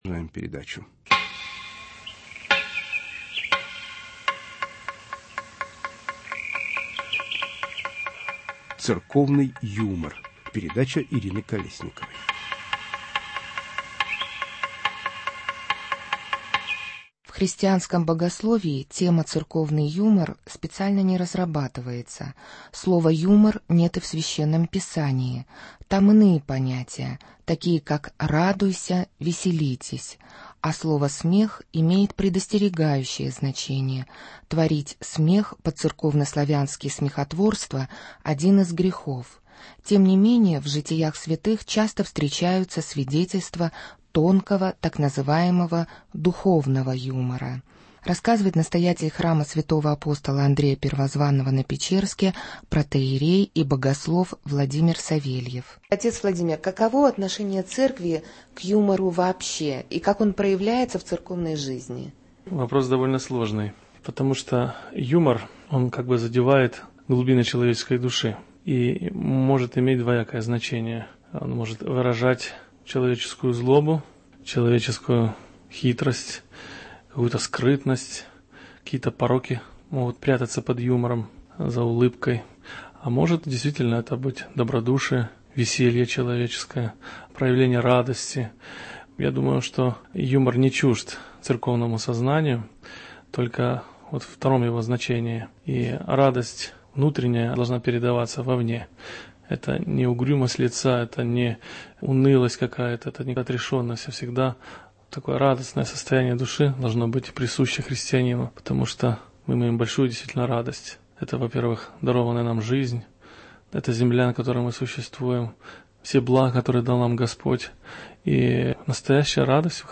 Церковный юмор. Три священнослужителя обсуждают значение юмора и смеха в церковной жизни.